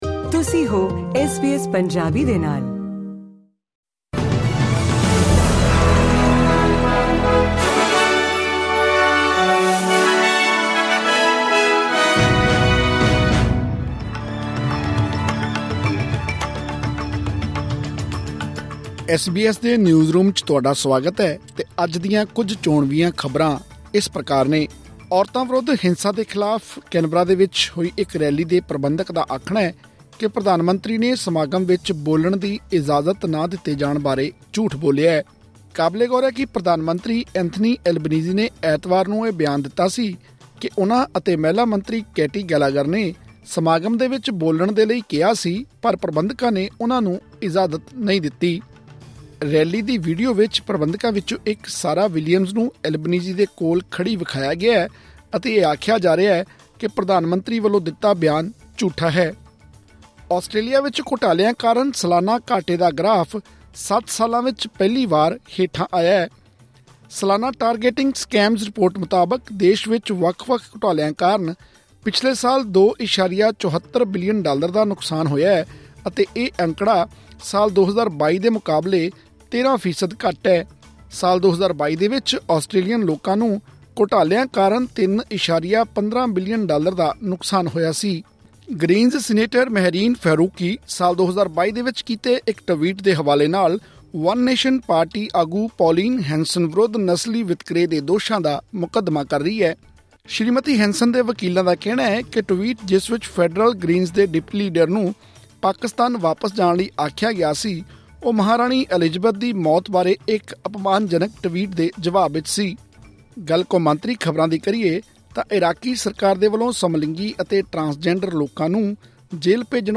ਐਸ ਬੀ ਐਸ ਪੰਜਾਬੀ ਤੋਂ ਆਸਟ੍ਰੇਲੀਆ ਦੀਆਂ ਮੁੱਖ ਖ਼ਬਰਾਂ: 29 ਅਪ੍ਰੈਲ, 2024